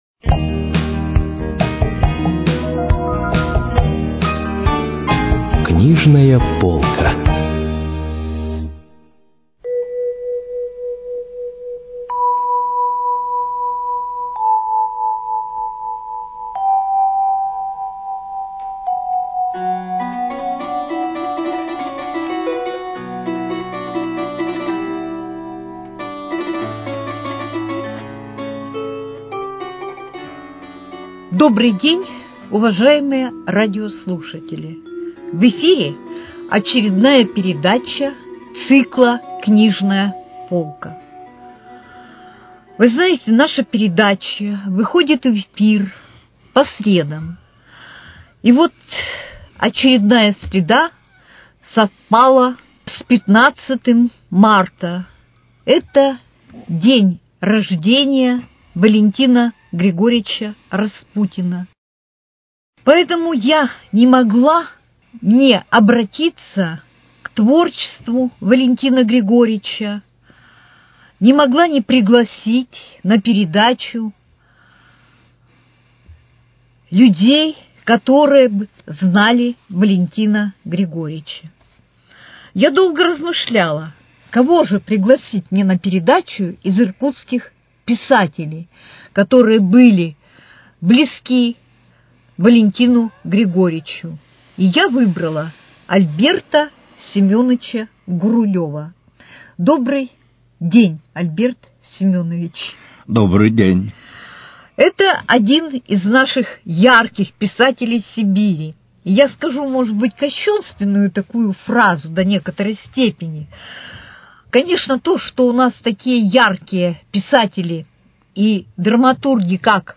Книжная полка: Беседа о творчестве Валентина Распутина